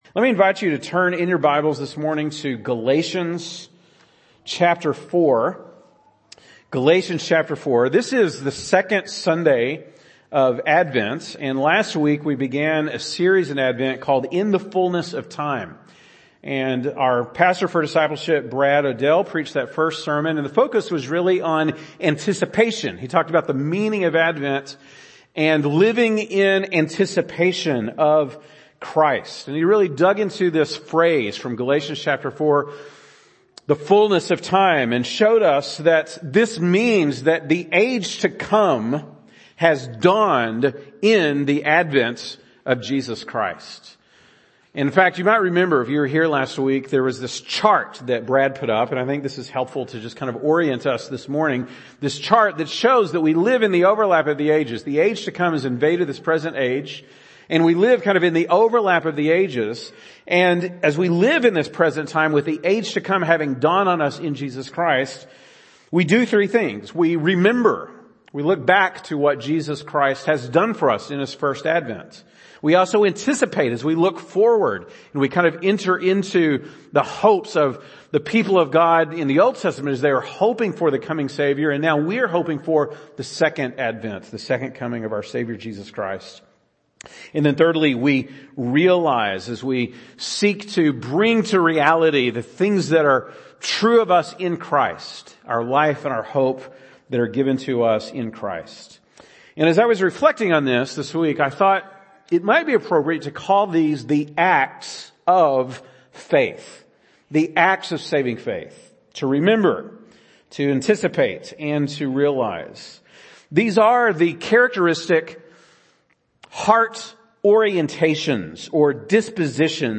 December 4, 2022 (Sunday Morning)